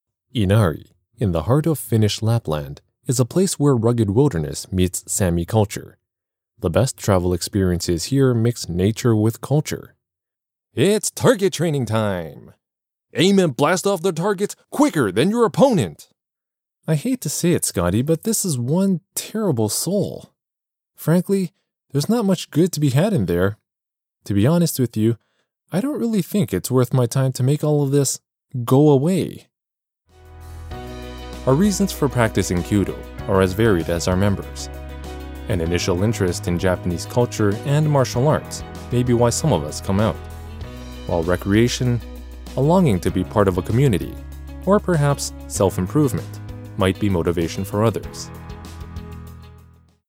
ボイスサンプル
北米英語ナレーター
男性
英語ﾈｲﾃｨﾌﾞのﾊﾞｲﾘﾝｶﾞﾙのため多少訛りあり